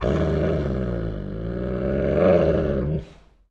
pdog_idle_2.ogg